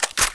Index of /fastdl/cstrike/sound/weapons
p90_cock.wav